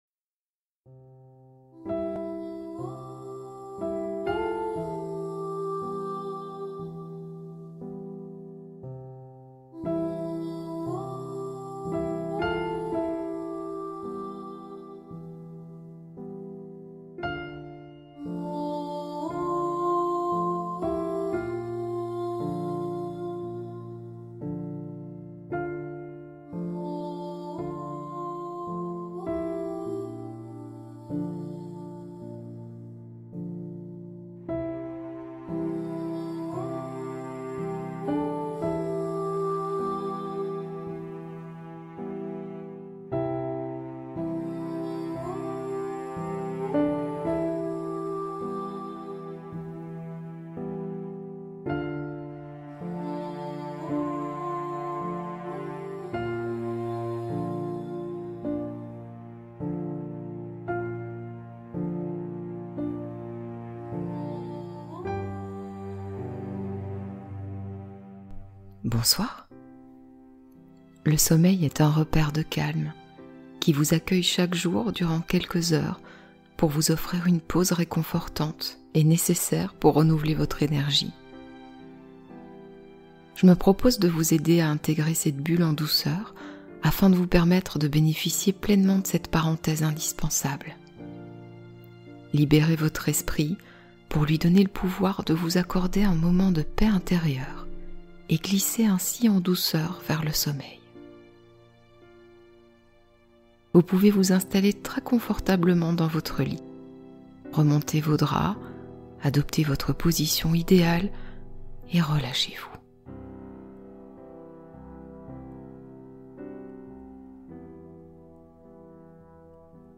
Paix intérieure : affirmations positives pour un état stable